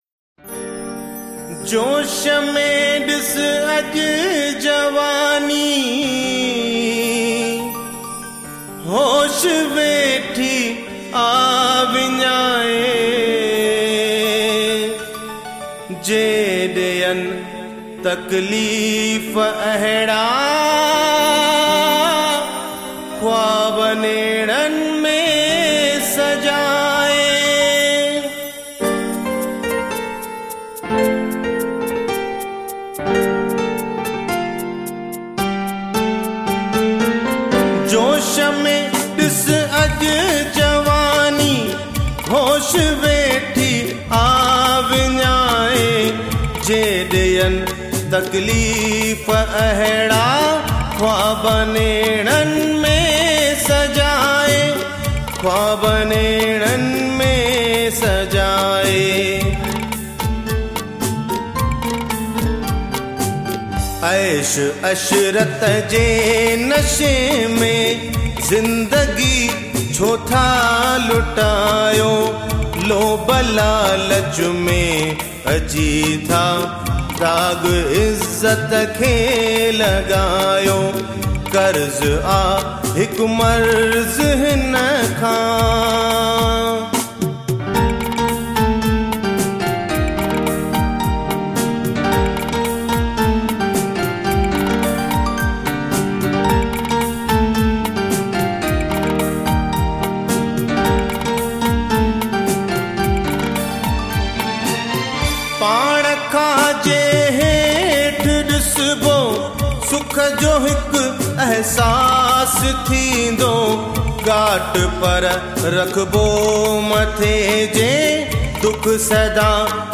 Sindhi Film.